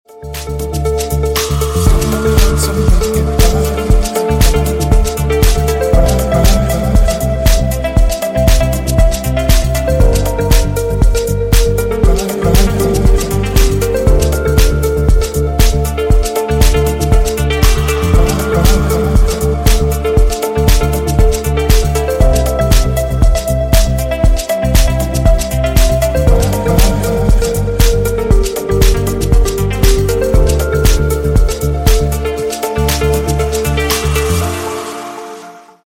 Клубные Рингтоны » # Спокойные И Тихие Рингтоны
Танцевальные Рингтоны